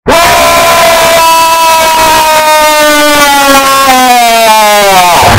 bear 5 scream Meme Sound Effect
bear 5 scream.mp3